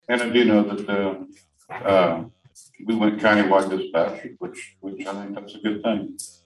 Commission Chair Greg Riat also supported the budget.